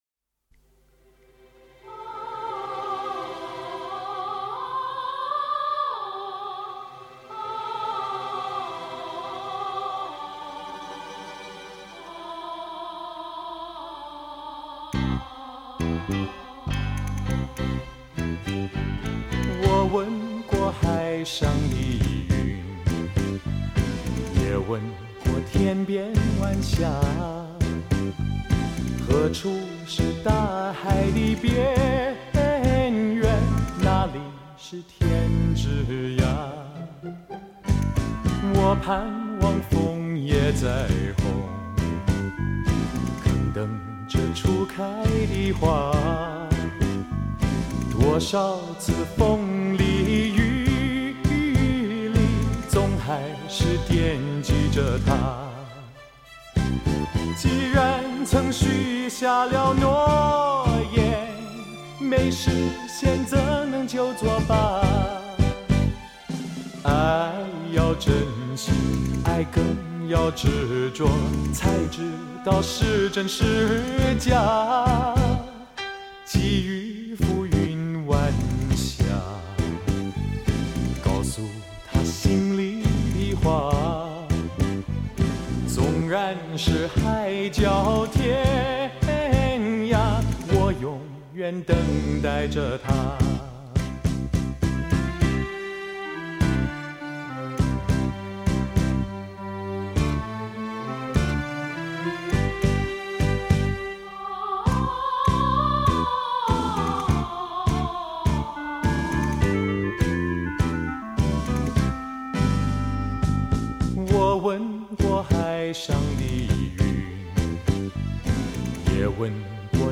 国语老歌金曲
HiFi高品质发烧CD试音碟
母带重制工程，混响更舒服，人声更加磁性、柔性！